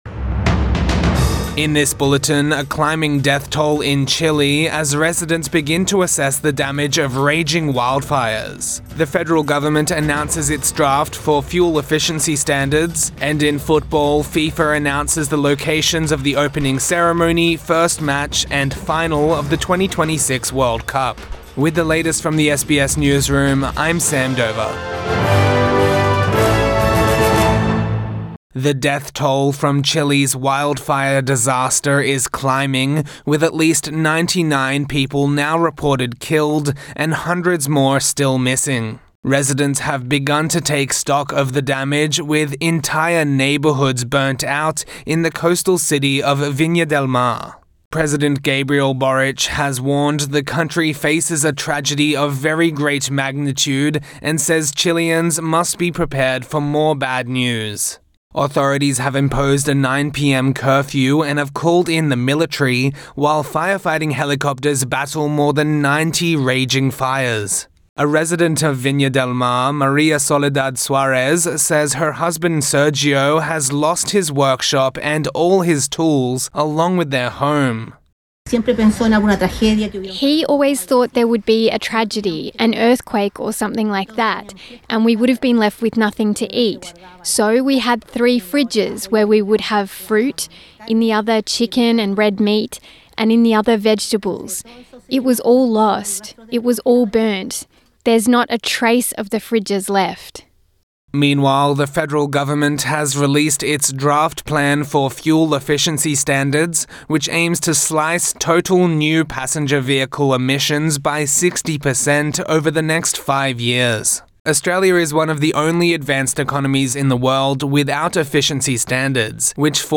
Midday News Bulletin 5 February 2024